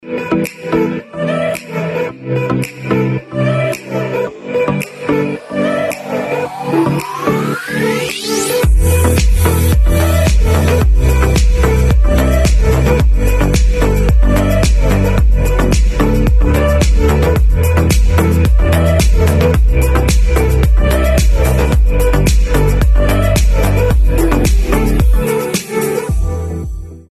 • Качество: 256, Stereo
красивые
без слов
tropical house